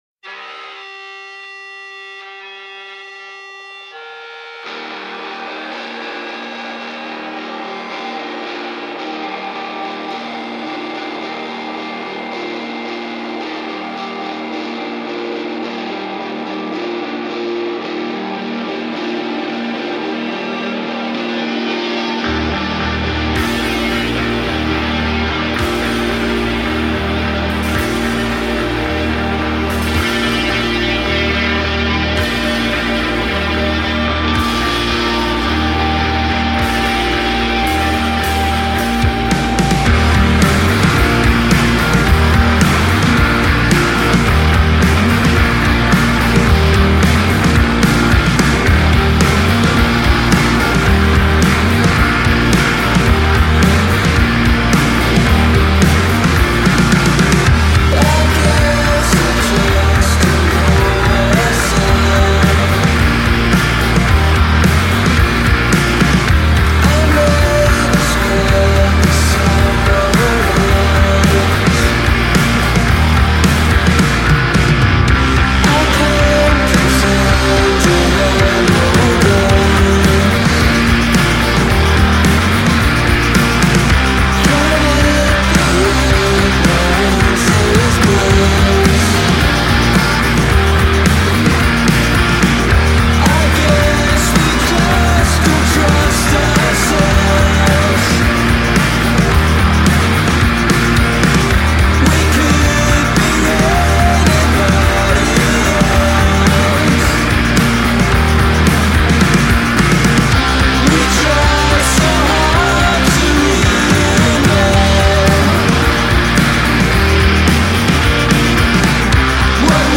expansive sound
switching seamlessly from post-rock